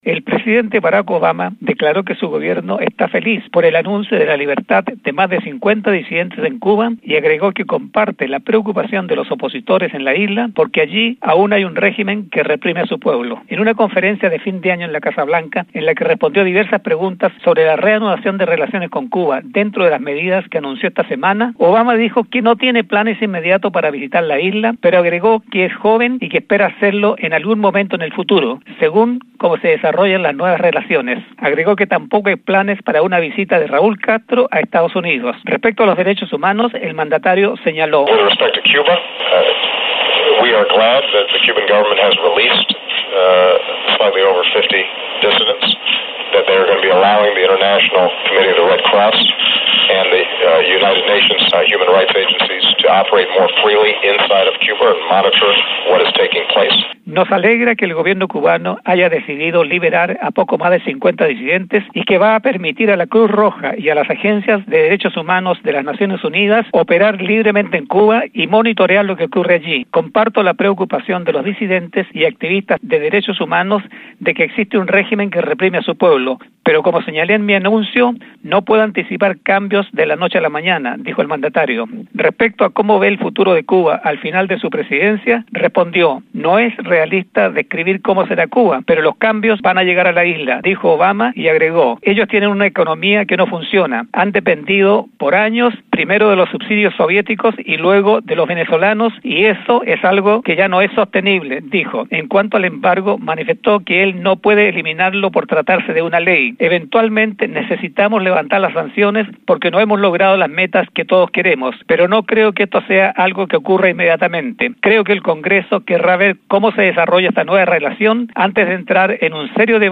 En una conferencia de prensa en la Casa Blanca, Obama sostuvo que Cuba aún tiene un Gobierno que reprime a sus ciudadanos y que no espera que eso cambie de un día para otro con la normalización de relaciones con Estados Unidos.